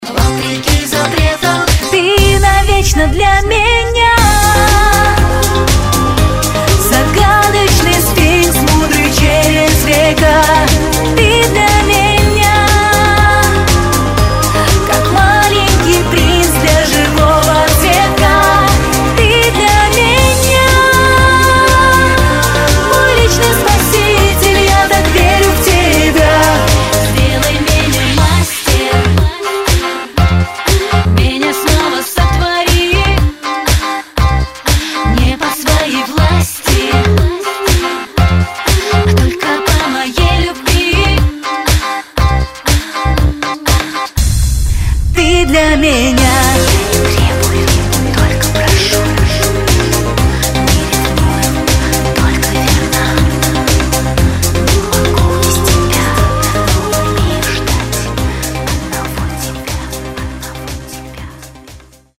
• Качество: 128, Stereo